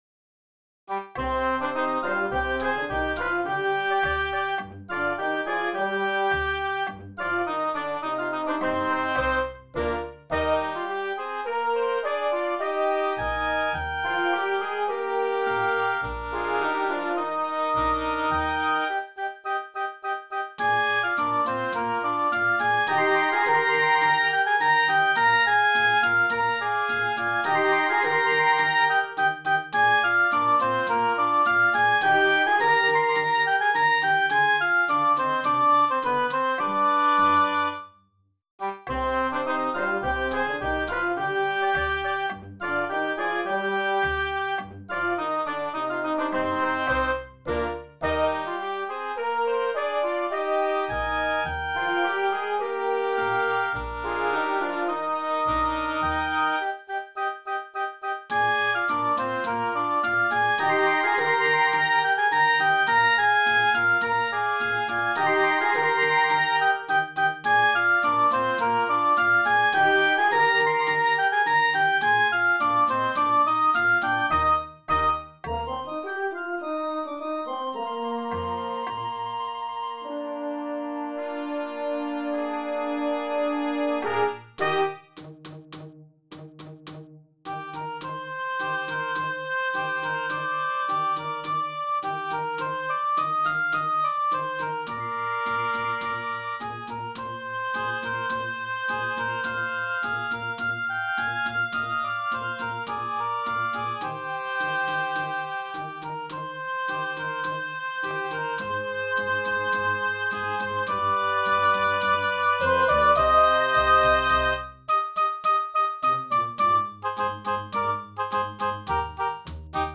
sardana